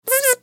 دانلود صدای حشره 8 از ساعد نیوز با لینک مستقیم و کیفیت بالا
جلوه های صوتی
برچسب: دانلود آهنگ های افکت صوتی انسان و موجودات زنده دانلود آلبوم صدای انواع حشرات از افکت صوتی انسان و موجودات زنده